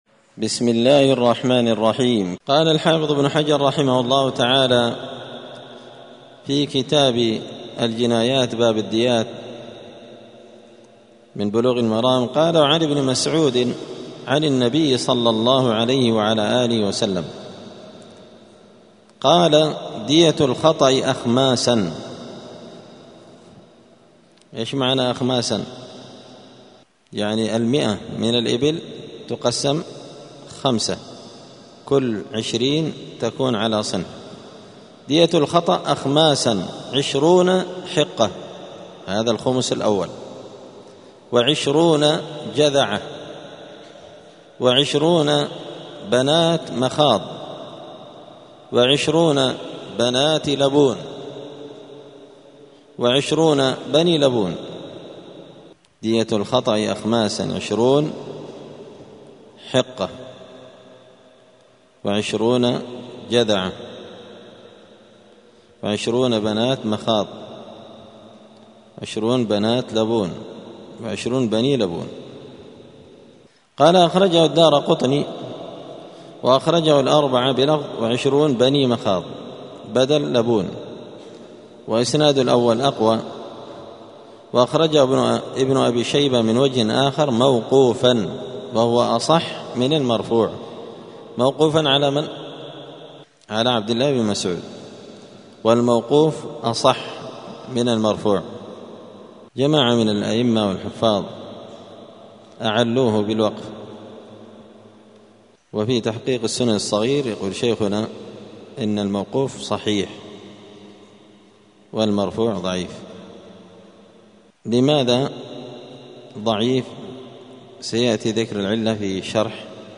*الدرس الحادي والعشرون (21) {باب الديات دية الخطأ والتغليظ فيها}*